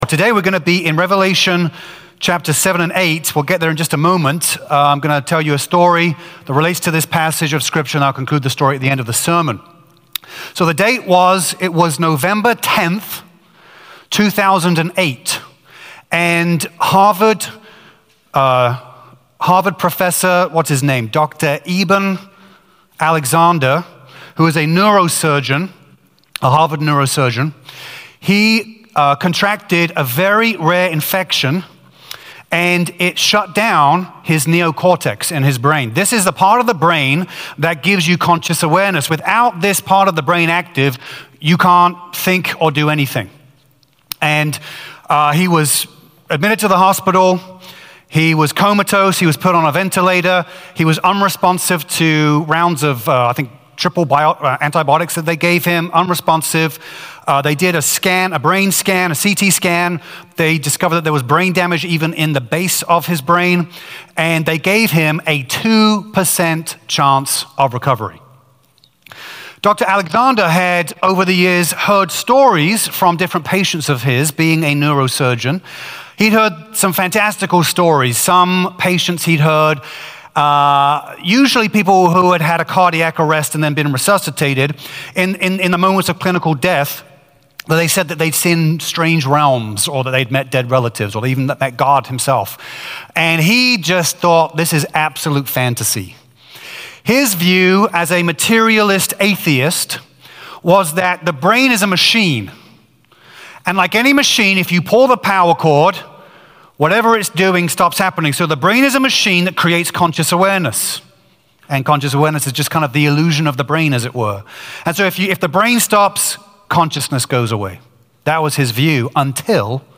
Oct-20-Full-Sermon-Podcast.mp3